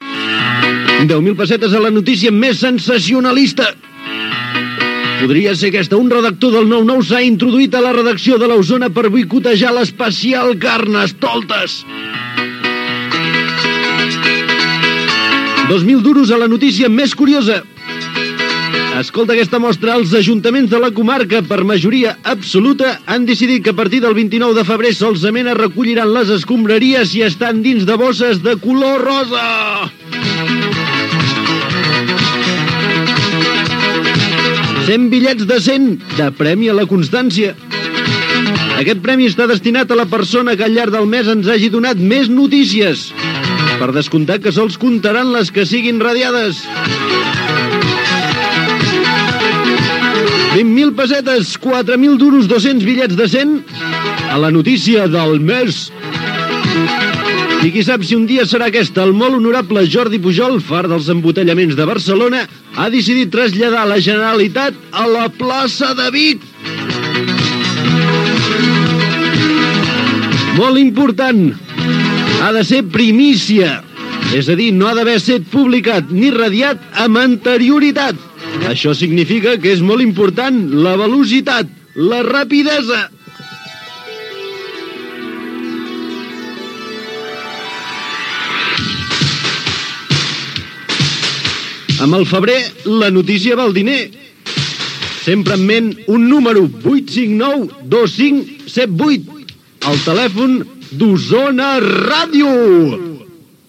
Banda FM